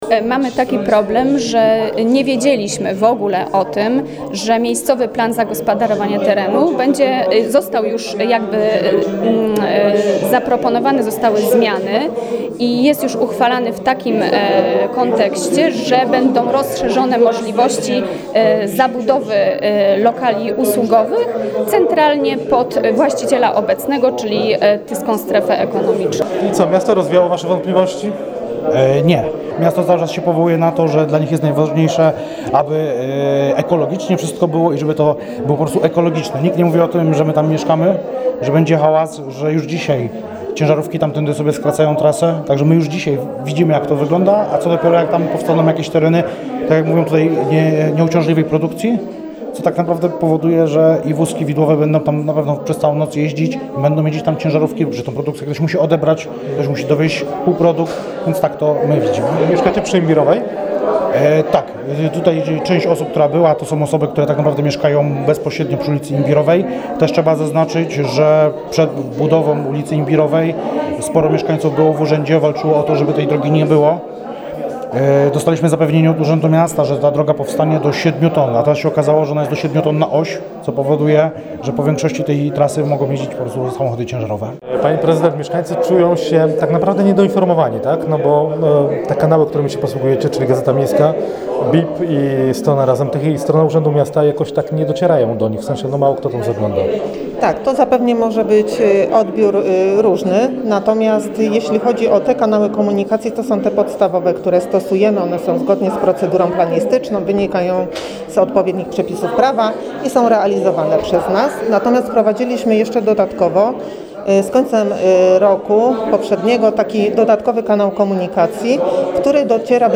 Burzliwe spotkanie w trakcie komisji Rady Miasta Tychy. Kilkudziesięciu mieszkańców przyszło zaprotestować przeciwko zmianom planu miejscowego na Wartogłowcu – dla ulicy Beskidzkiej, Imbirowej i Oświęcimskiej w Tychach.
Posłuchaj, co powiedzieli nam mieszkańcy i urzędnicy: